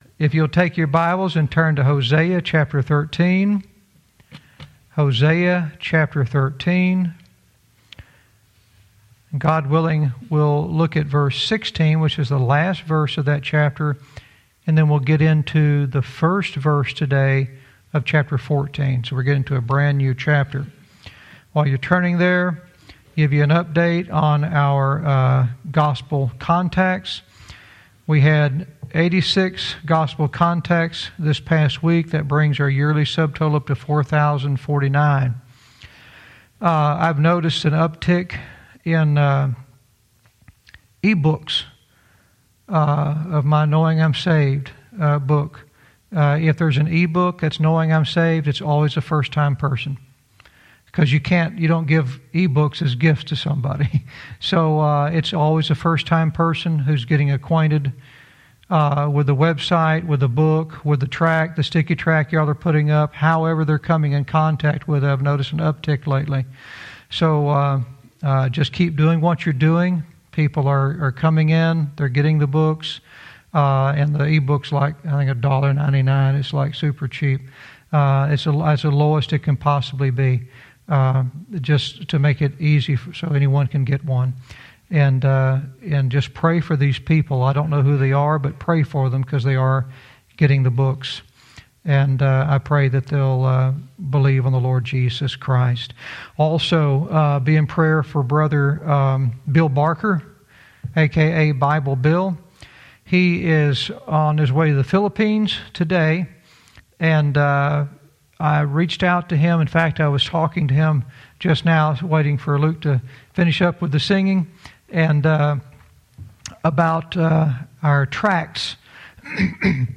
Verse by verse teaching - Hosea 13:16-14:1 "Rise and Walk"